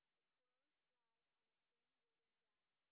sp15_white_snr0.wav